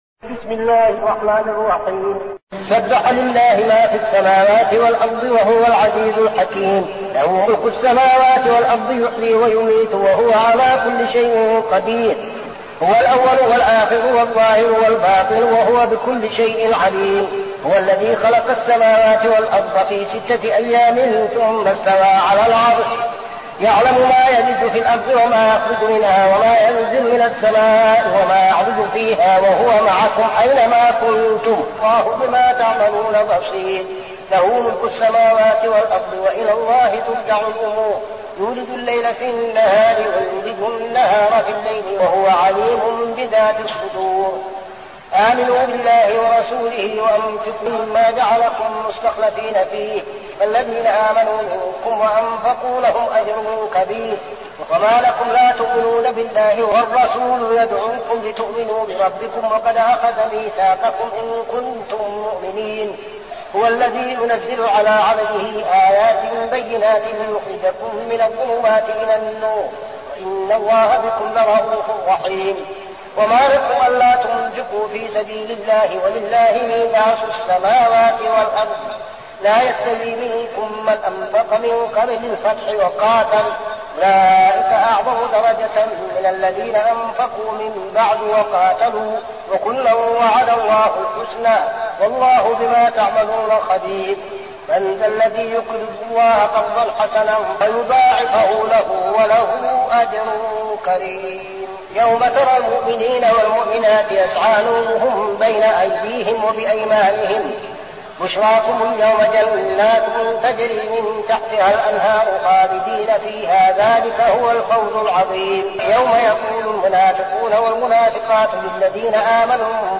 المكان: المسجد النبوي الشيخ: فضيلة الشيخ عبدالعزيز بن صالح فضيلة الشيخ عبدالعزيز بن صالح الحديد